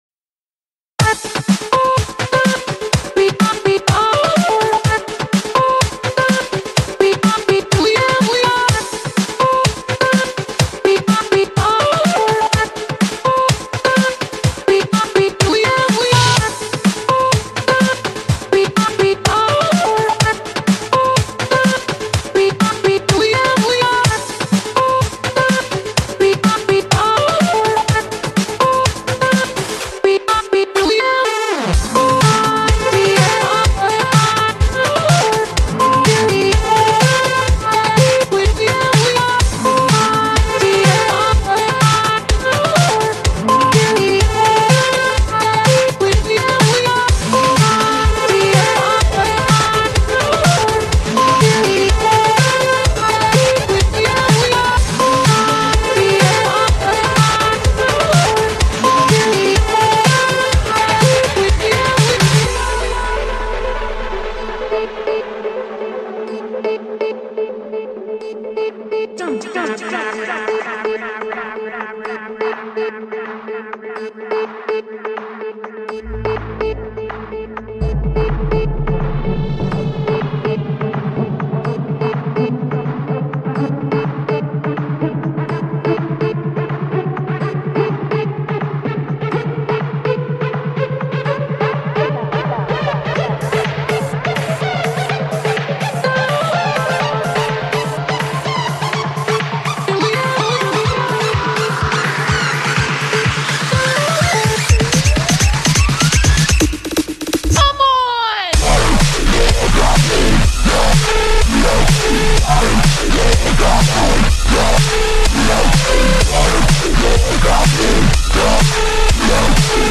genre:remix